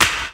Snare
Original creative-commons licensed sounds for DJ's and music producers, recorded with high quality studio microphones.
Short Snare Sound E Key 16.wav
short-snare-sound-e-key-16-ntv.wav